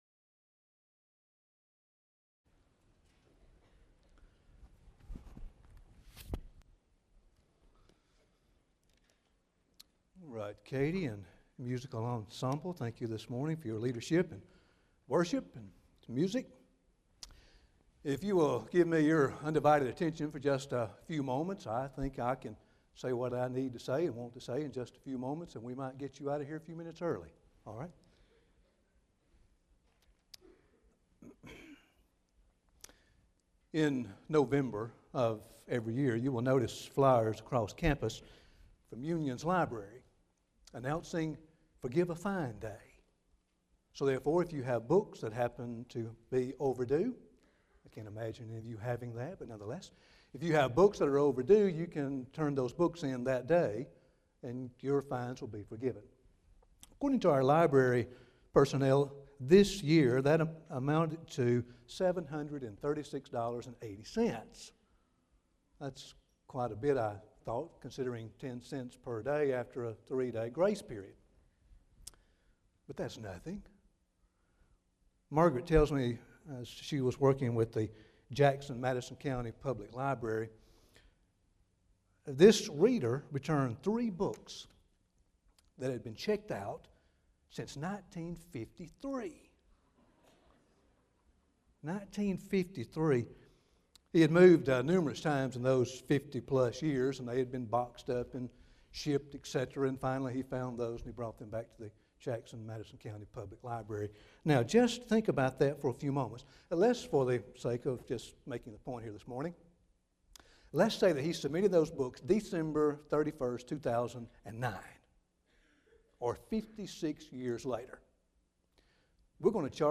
Address: Lessons of Forgiveness - the Joseph story